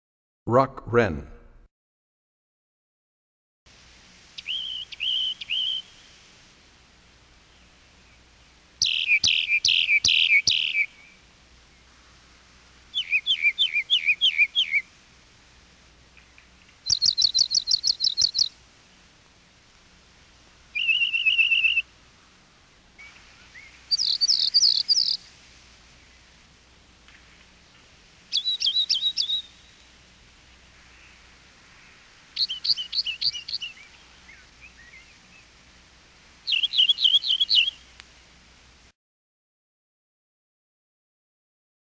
74 Rock Wren.mp3